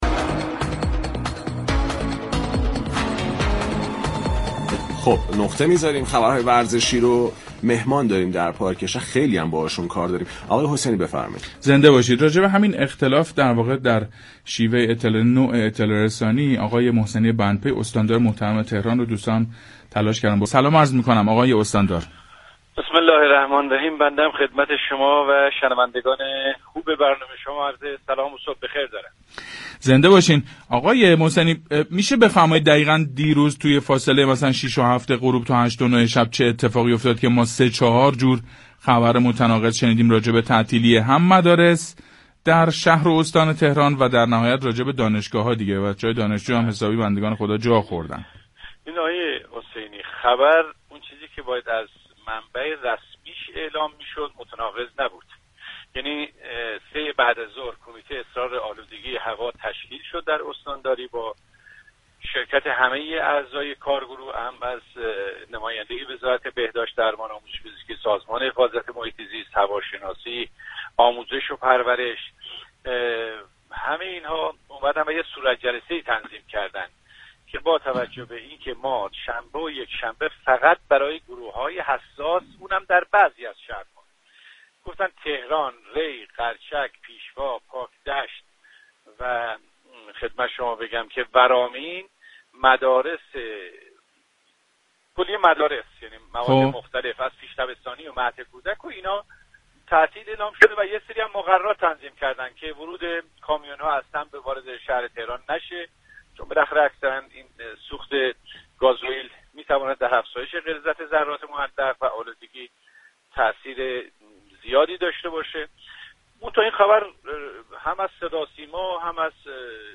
محسنی بندپی استاندار تهران در برنامه پارك شهر درباره اطلاع‌رسانی اشتباه خبرگزاری‌ها درباره تعطیلی امروز و فردا توضیح داد.